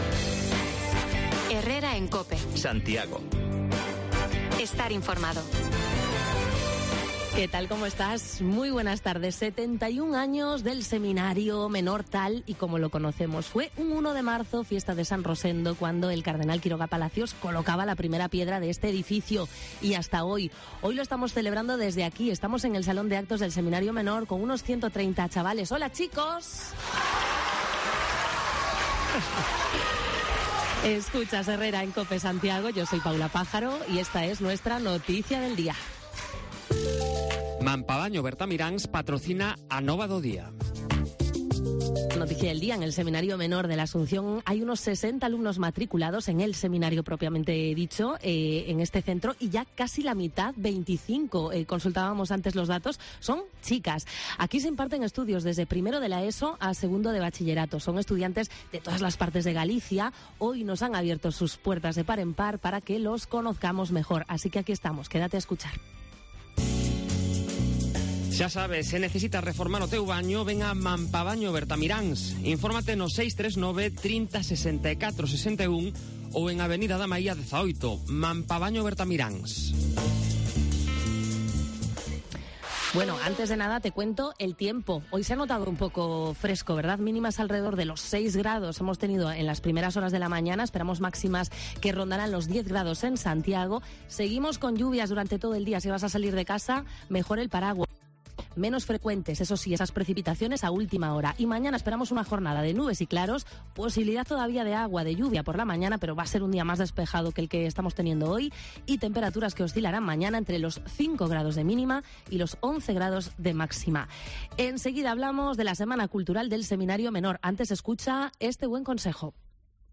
Arranca la Semana Cultural del Seminario de Belvís y Cope Santiago emite programación especial desde allí, con algunos de los protagonistas